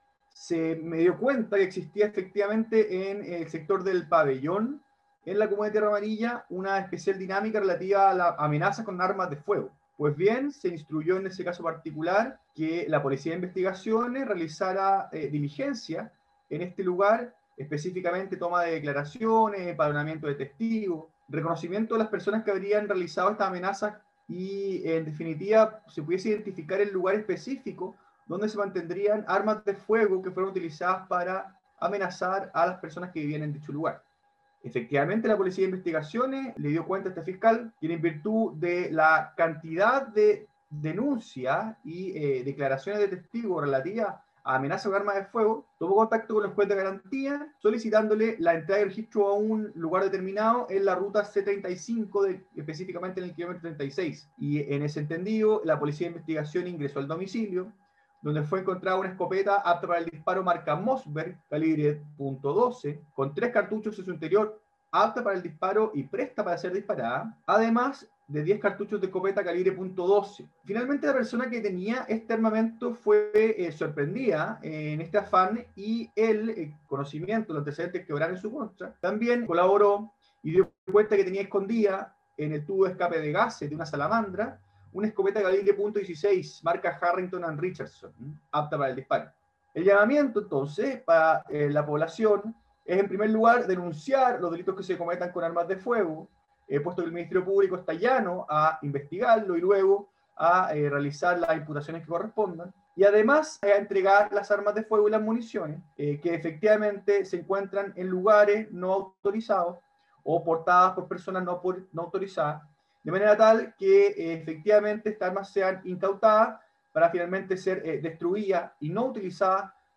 CUÑA-FISCAL-RENAN-GALLARDO.mp3